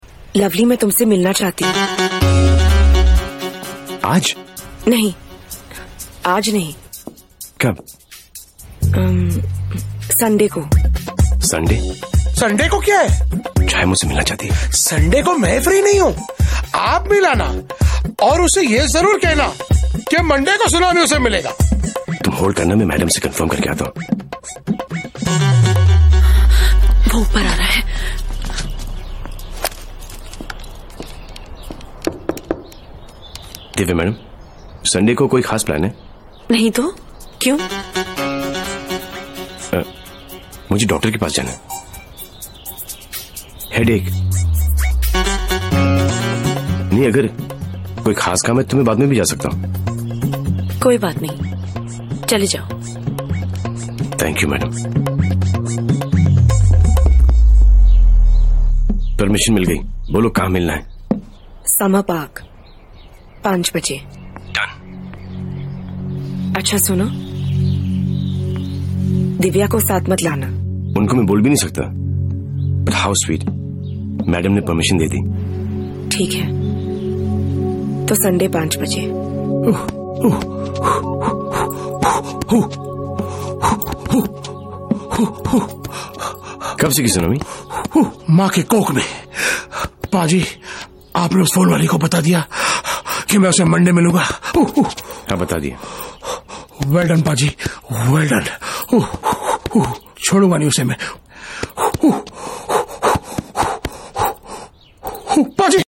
Salman Khan best movie scene sound effects free download